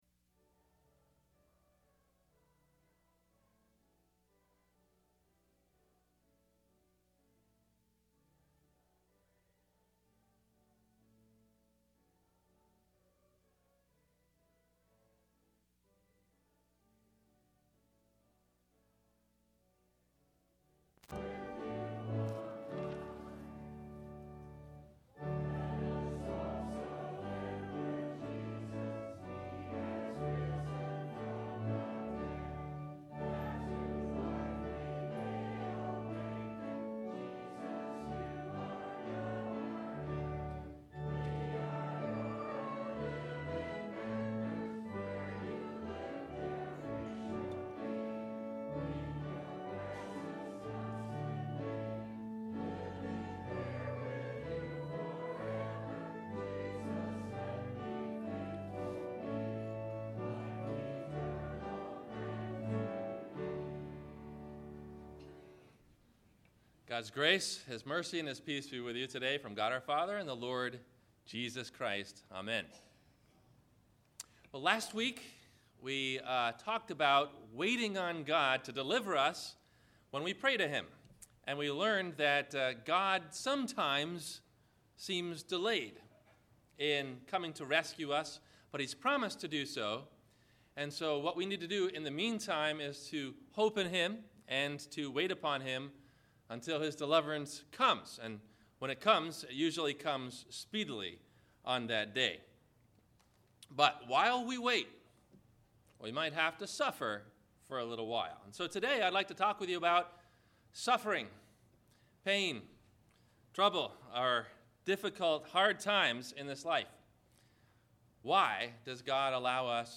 Why Does God Allow Suffering? – Sermon – September 30 2012